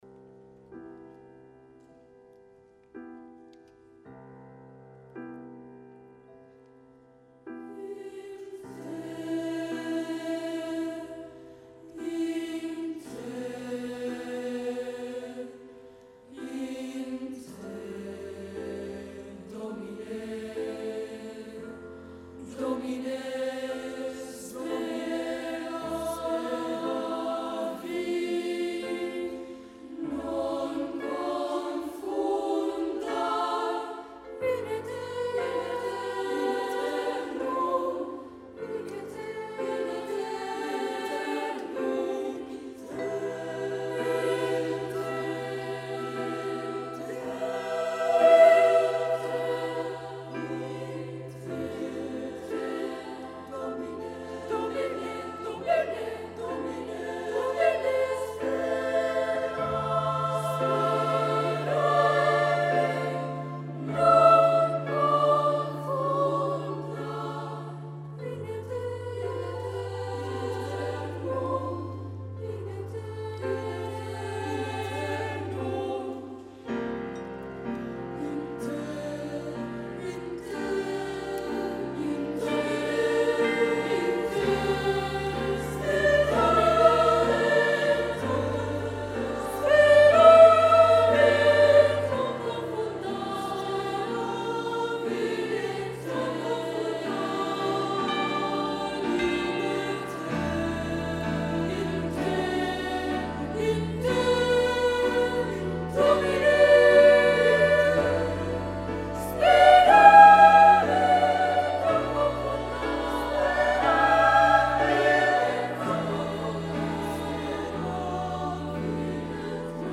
Ahir, tal i com ja es va anunciar fa dies a IFL, va tenir lloc a l’auditori AXA de l’Illa Diagonal el darrer concert de la temporada del Cor Vivaldi, el concert d’estiu que enguany comptava amb dos cors invitats arribats dels Estats Units (Piedmont East Bay Children’s Choir) i Dinamarca (Copenhagen girls choir) per interpretar a la segona part el Te Deum d’Albert Guinovart, mentre que a la primera, cadascuna de les formacions va fer un tast del seu repertori, sent el Vivaldi qui va compartir amb cadascun dels cors invitats, una obra en conjunt.
Sense desmerèixer gens la vàlua d’aquestes dues formacions i malgrat l’inici vacil·lant del Vivaldi, continuo pensant que la màgia de la sonoritat del cor català, amb una emissió adulta, ferma, potent i contundent, res té a veure amb la immensa majoria dels cors de veus blanques.
Escolteu per acabar el deliciós “In, te Domine” del Te Deum de Guinovart tal i com el van interpretar ahir el Cor Vivaldi, el Piedmont East Bay Children’s Choir i el Copenhagen girls choir, amb Guinovart al Piano
percussió